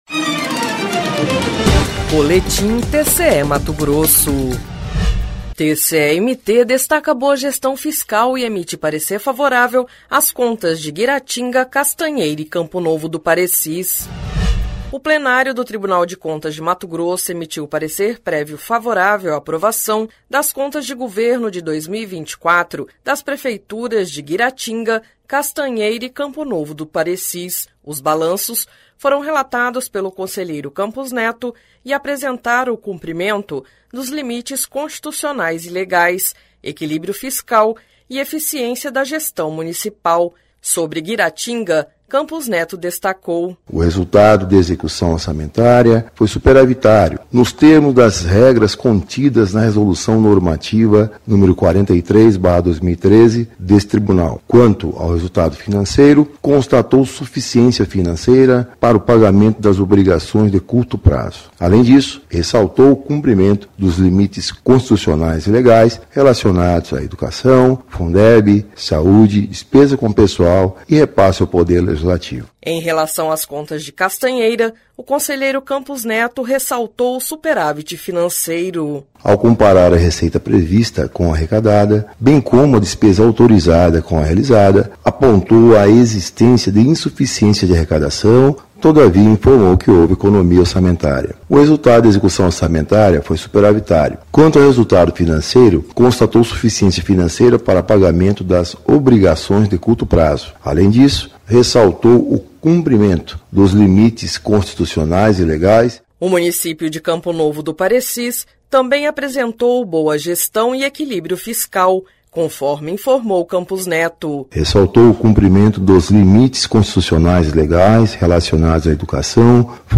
Sonora: Campos Neto – conselheiro do TCE-MT